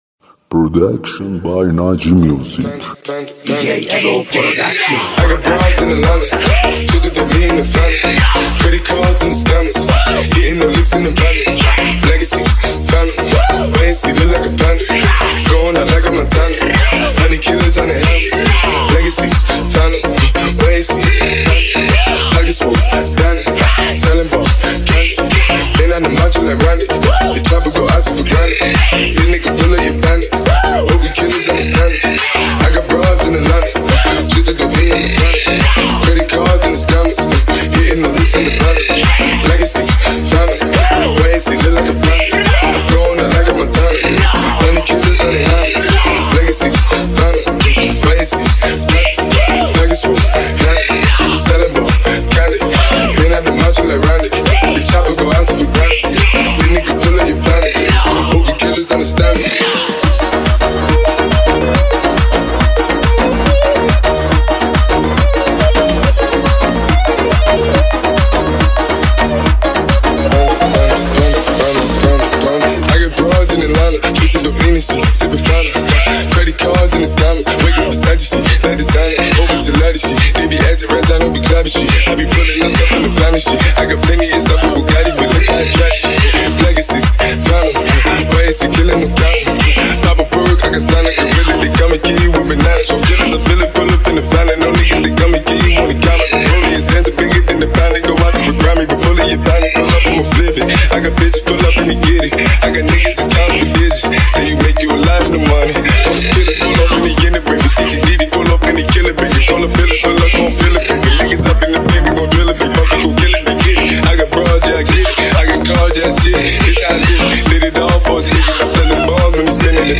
ریمیکس
ریمیکس شاد تریبال خارجی رقصی